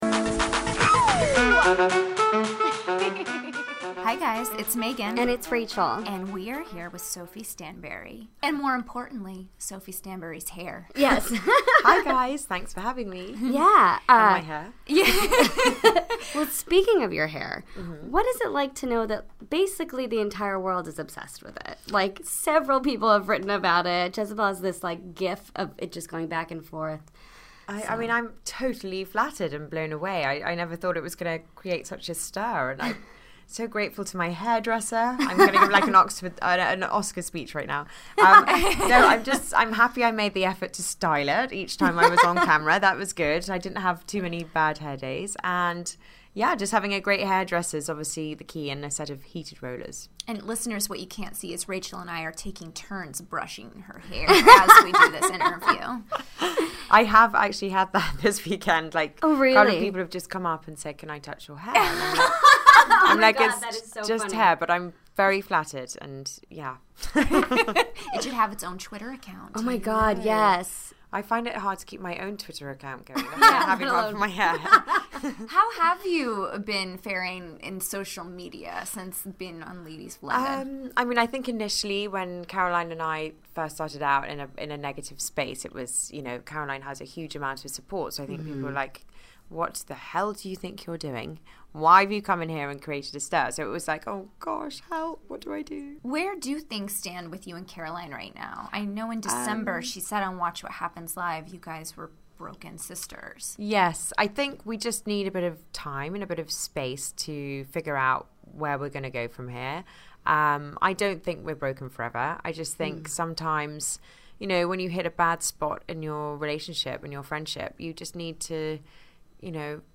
Thursday, January 19, 2017 - From Bravo HQ in New York City, we sat down with Ladies of London's Sophie Stanbury to discuss dating, friendship, and of course, her famous hair. Find out where things stand with her ex-sister-in-law Caroline Stanbury and who she counts as her best Bravolebrity friends.